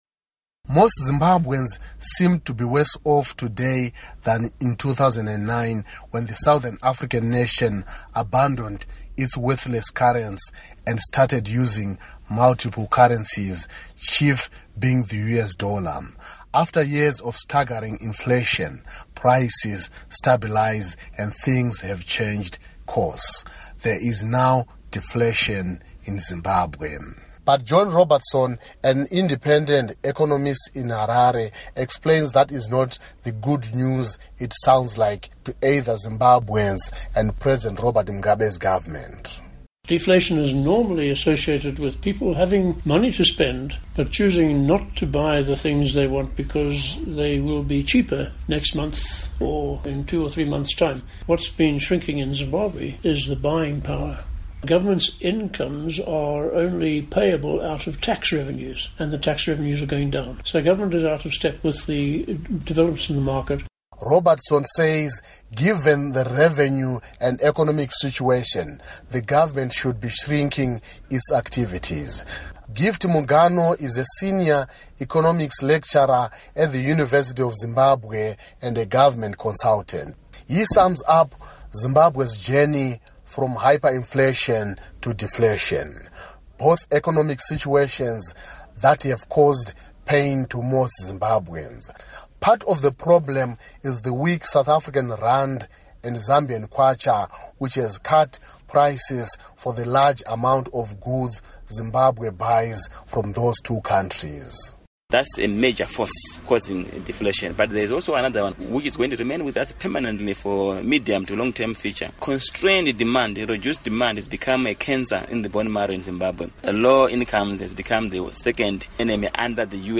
Report on Zimbabwe Economy